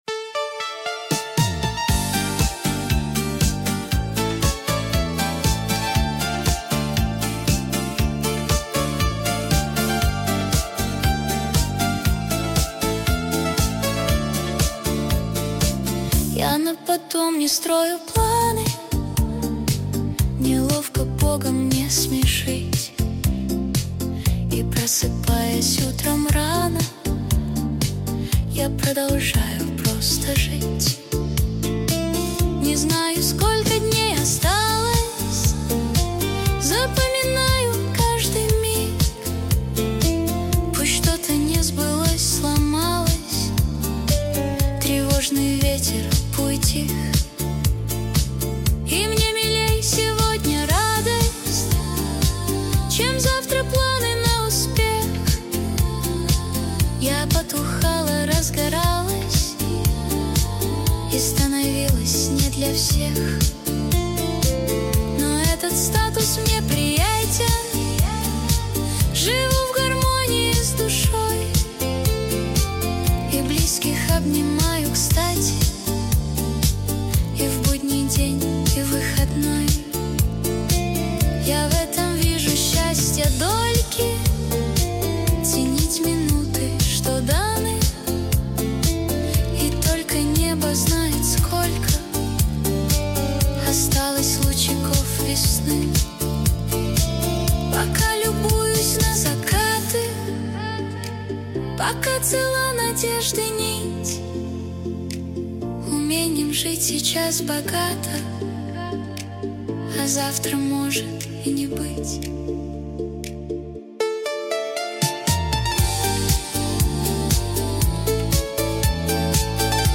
Стихи, Песни Суно ИИ